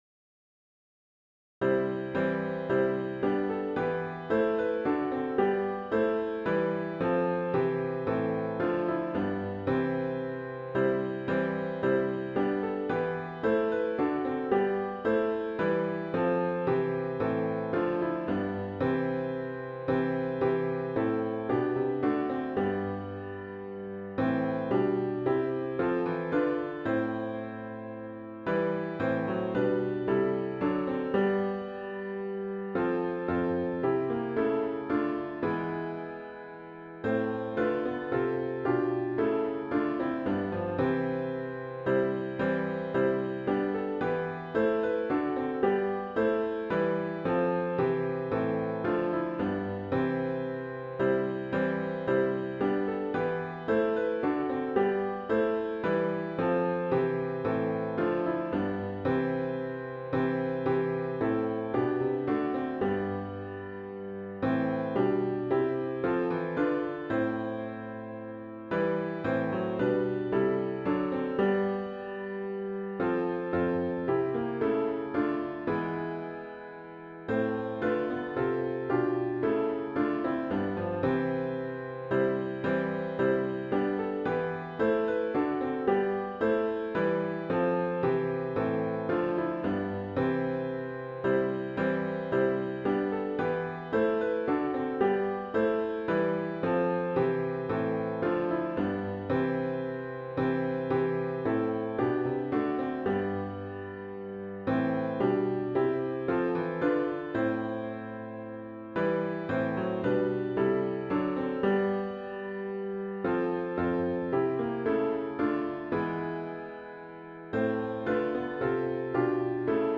OPENING HYMN   “A Mighty Fortress Is Our God”   GtG 275